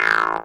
synTTE55007shortsyn-A.wav